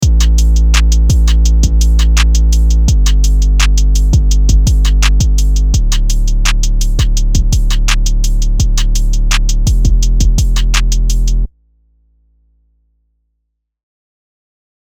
Trap Drum Kit 09 + 808 Mad
TrapDrumKit09808Mad.mp3